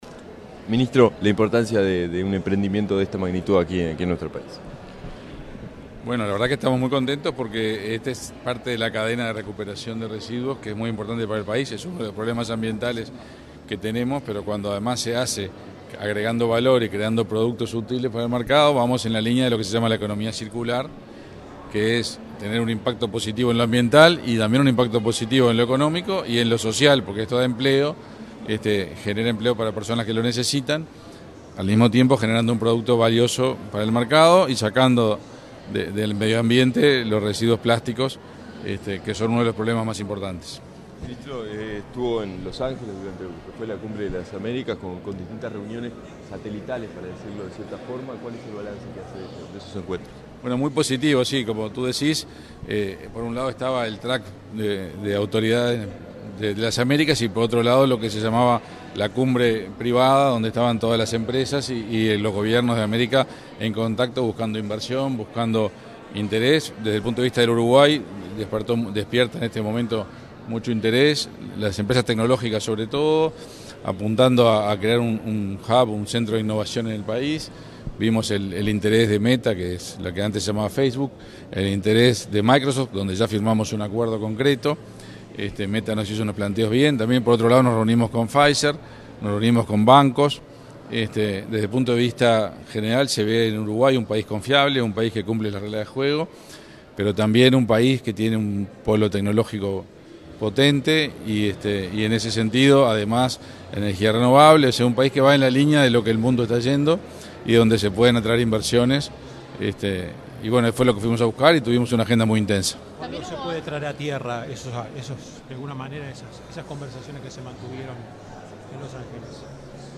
Palabras del ministro de Industria, Energía y Minería, Omar Paganini
Palabras del ministro de Industria, Energía y Minería, Omar Paganini 13/06/2022 Compartir Facebook X Copiar enlace WhatsApp LinkedIn Una empresa de reciclaje inauguró este 13 de junio una planta en la que se procesará 9 toneladas diarias de residuos y empleará a participantes de los programas del Ministerio de Desarrollo Social (Mides). Al evento asistió el ministro de Industria, Energía y Minería, Omar Paganini.